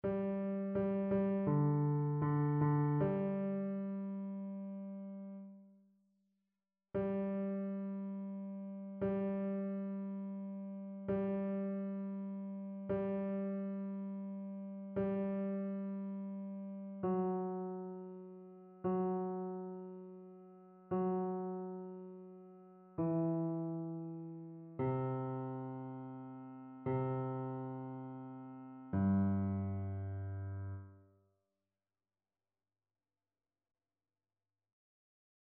Chœur
Basse